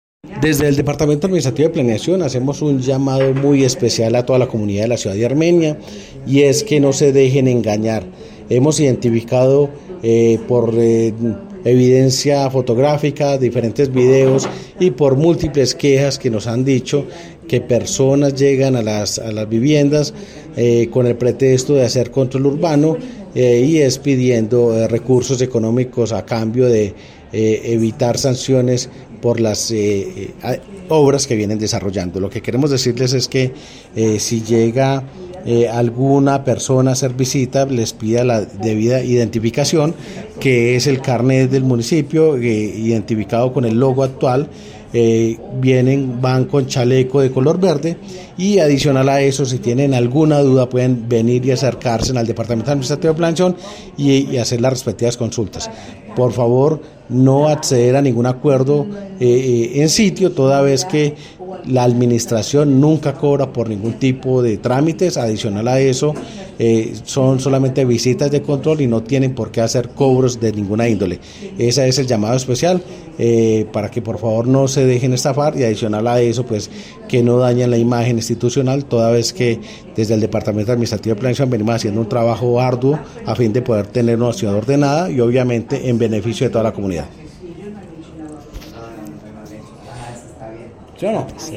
Audio de: Diego Fernando Ramírez, director de Planeación Municipal